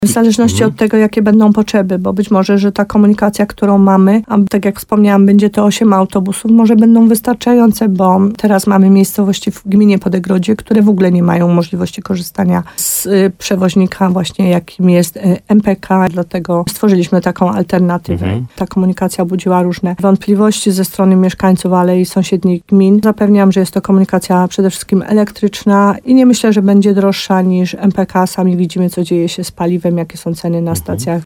– Nowa komunikacja całkowicie ruszy 1 stycznia 2027, ale jeśli będzie potrzeba, to przedłużymy konkretne umowy z MPK na dalsze funkcjonowanie kilku tras – powiedziała w programie Słowo za Słowo na antenie RDN Nowy Sącz wójt gminy Podegrodzie Małgorzata Gromala.